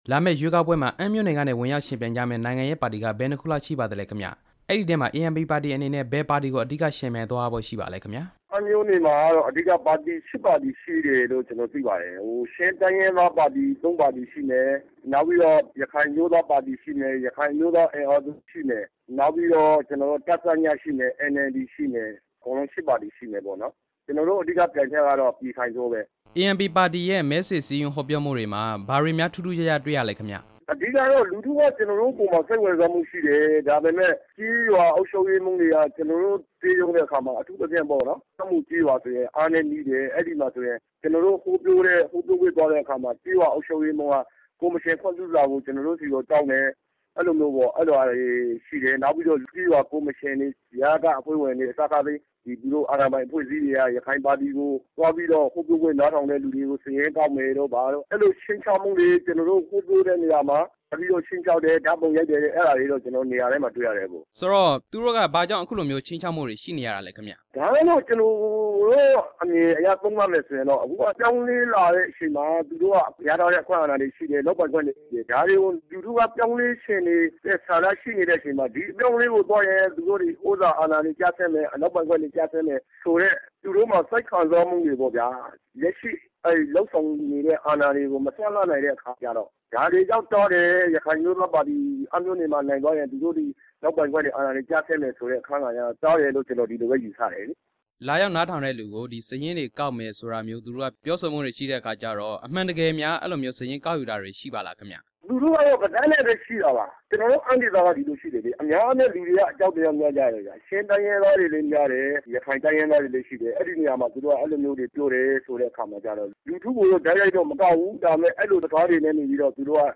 အမ်းမြို့နယ်အတွင်း ရခိုင်အမျိုးသားပါတီရဲ့ မဲဆွယ်မှုအခက်အခဲ မေးမြန်းချက်